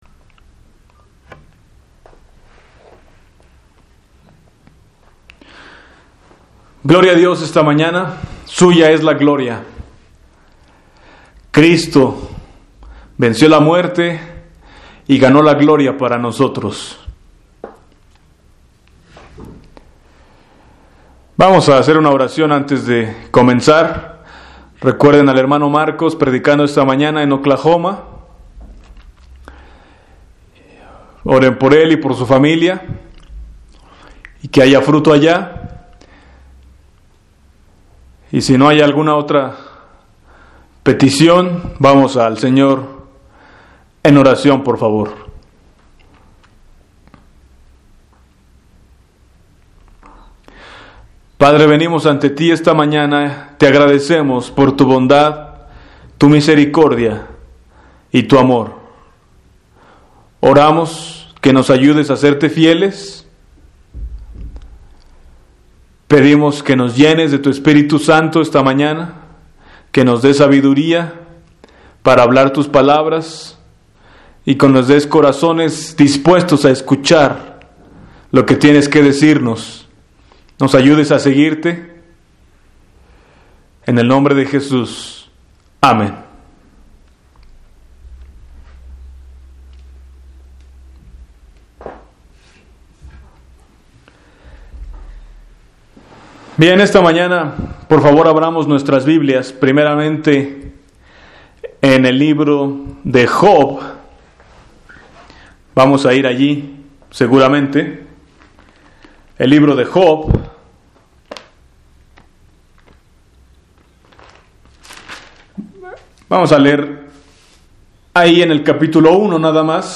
Para bajar a tu computadora o a otro dispositivo, haz click derecho en el t�tulo del serm�n, y luego selecciona �guardar como� (Internet explorer) o �guardar link como� (Netscape) Morir para Ganar Morir Para Ganar